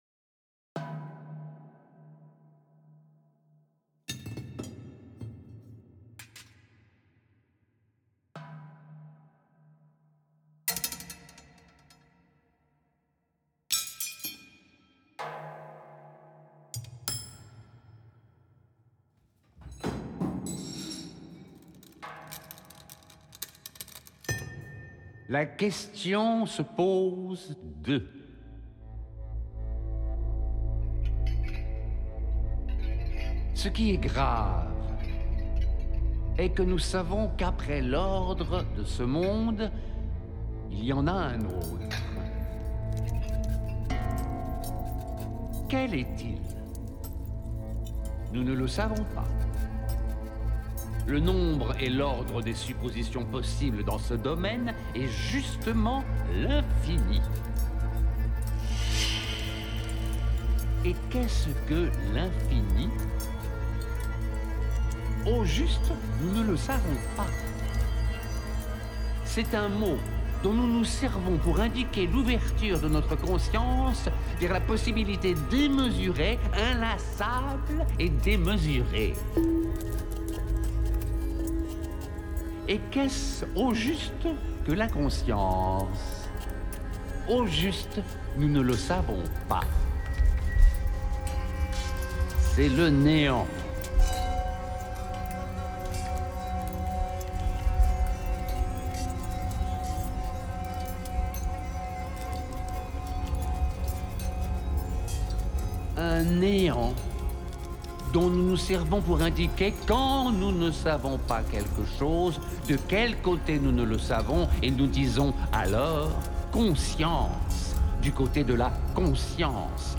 Texte dit par :